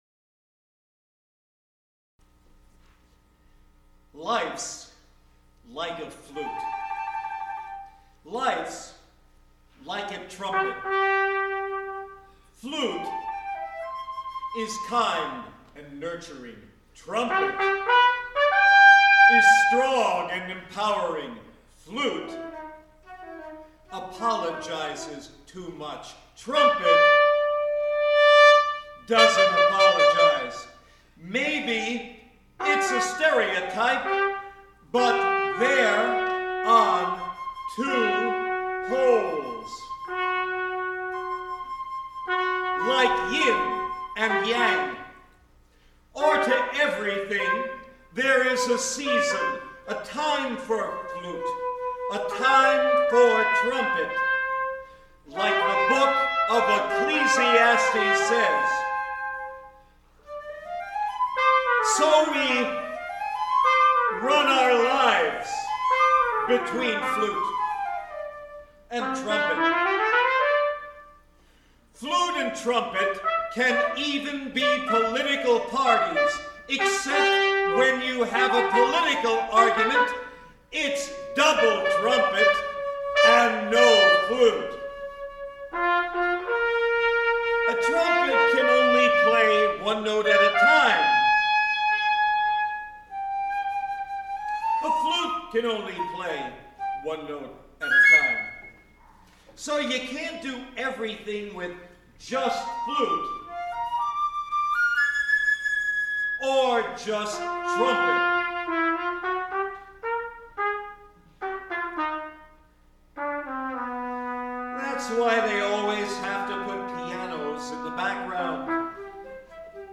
voice, flute & trumpet 5 min.